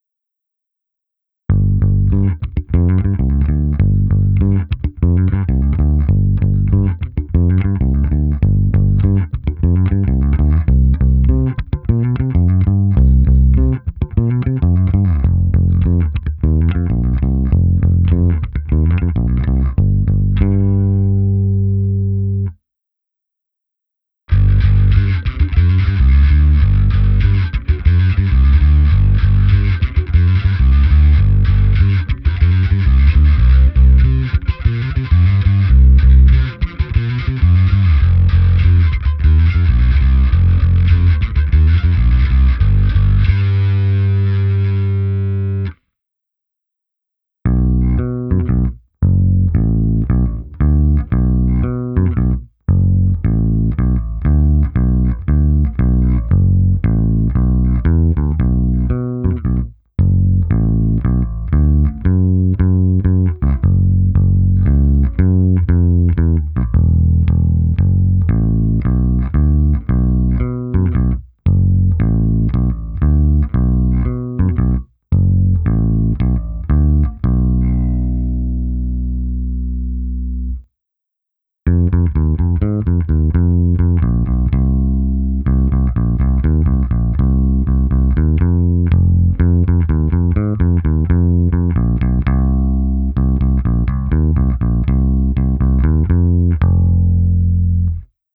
Nahrávka s hlazenými strunami Thomastik, protaženo preampem Darkglass Harmonic Booster, kompresorem TC Electronic SpectraComp a preampem se simulací aparátu Darkglass Microtubes X Ultra. Použil jsem i zkreslení. Hráno nad snímačem, na obě cívky, s lehce přidanými basy a výškami a lehce staženými středy. Má to parádní punch, attack, hodně mě tenhle zvuk baví.